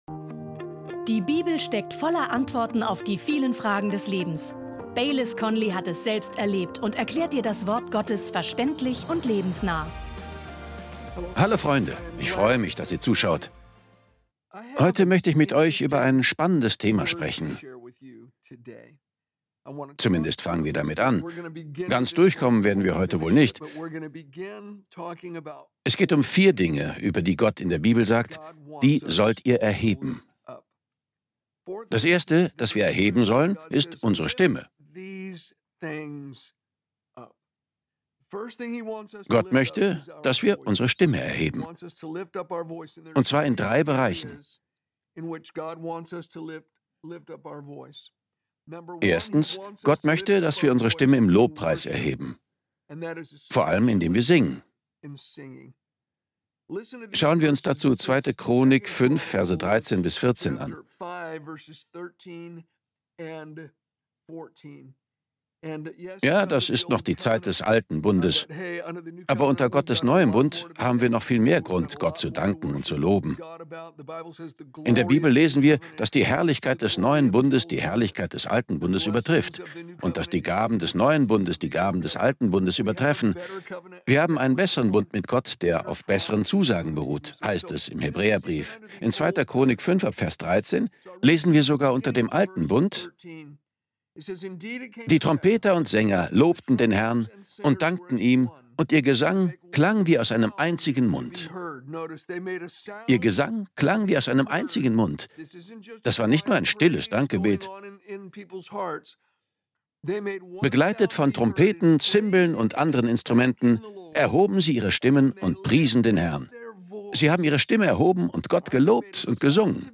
Beschreibung vor 6 Monaten Sind unsere Lieder im Gottesdienst nur Gesang? In dieser kraftvollen Predigt erfährst du, warum Gott möchte, dass du deine Stimme für ihn erhebst.